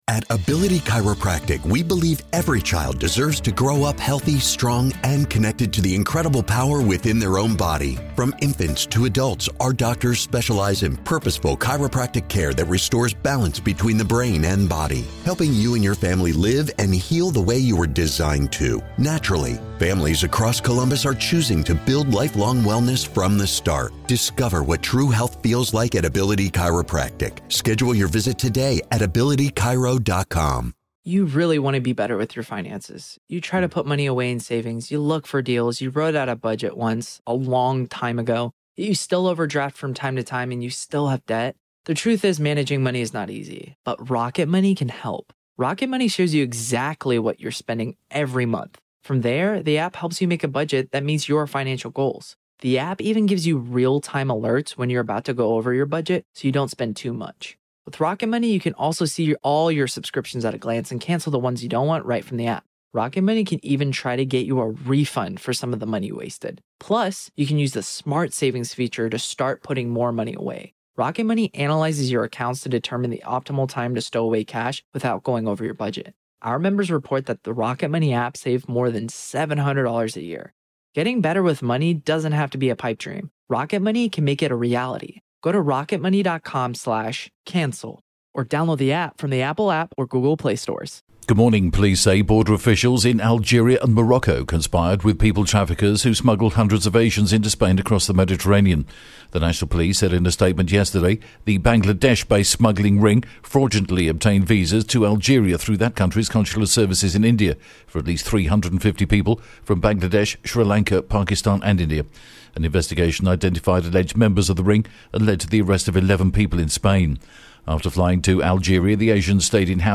The latest Spanish News Headlines in English: May 3rd